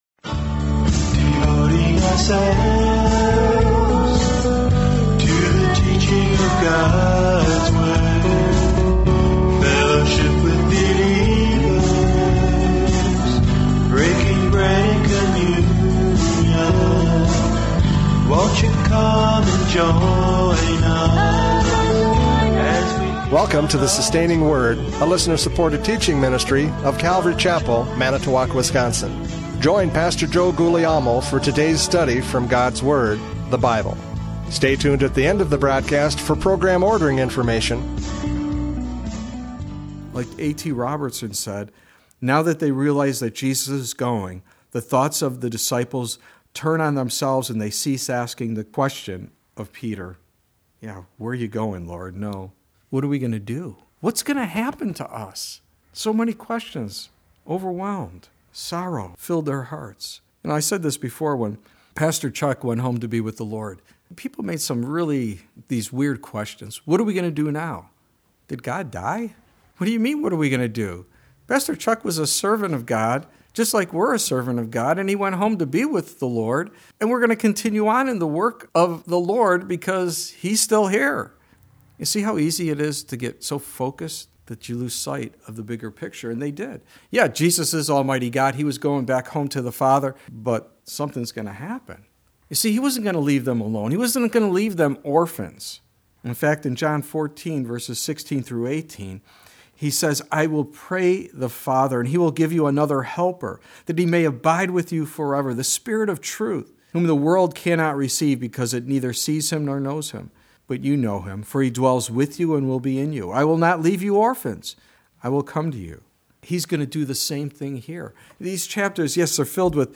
John 16:1-7 Service Type: Radio Programs « John 16:1-7 The Warning!